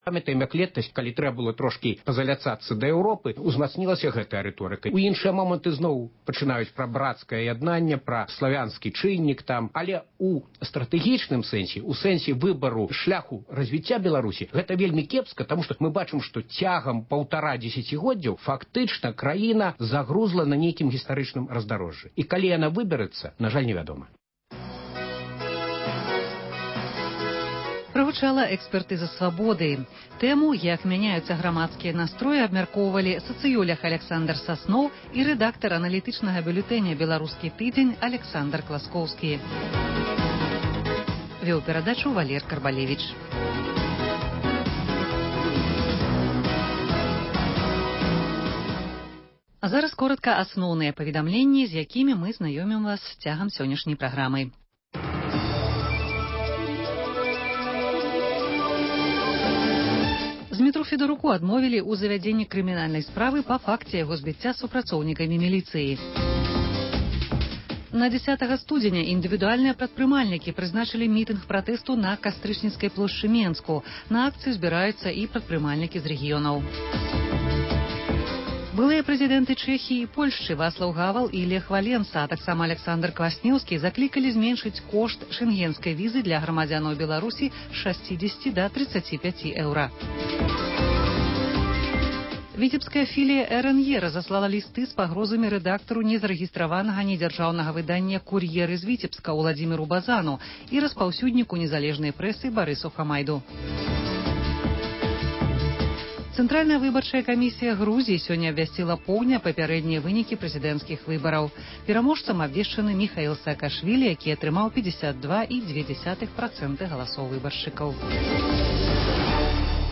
Онлайн- канфэрэнцыя